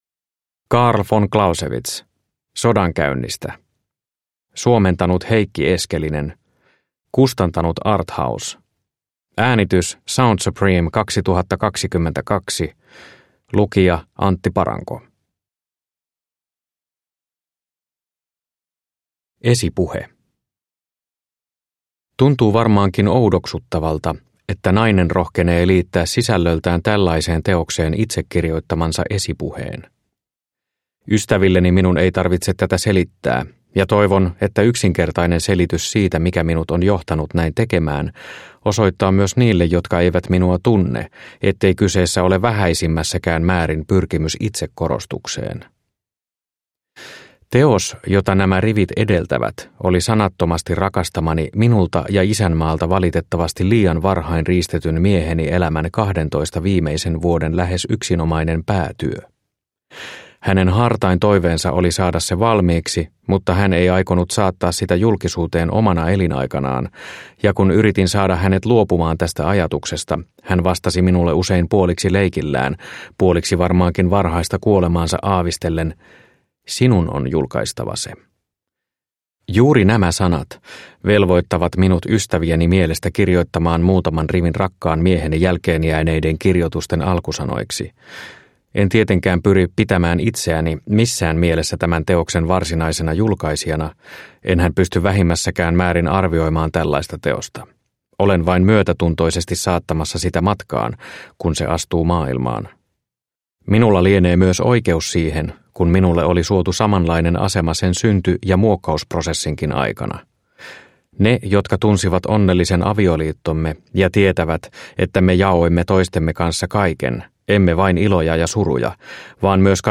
Sodankäynnistä – Ljudbok – Laddas ner